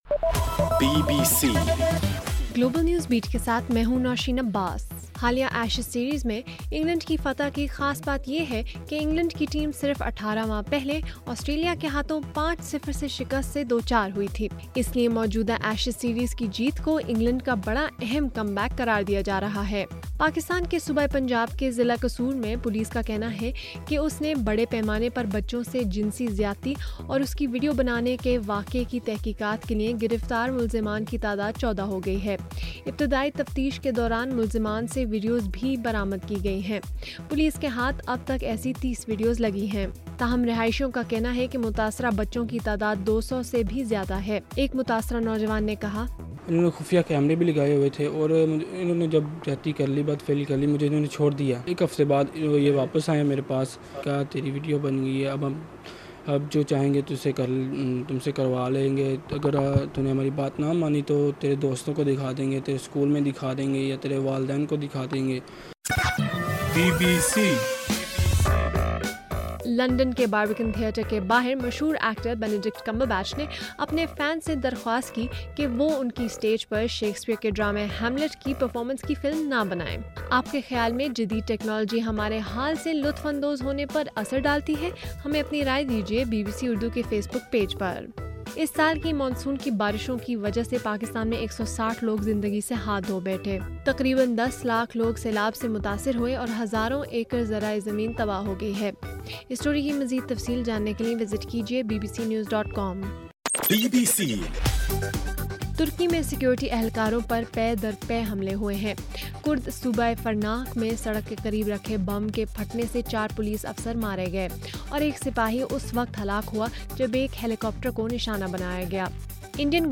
اگست 10: رات 10 بجے کا گلوبل نیوز بیٹ بُلیٹن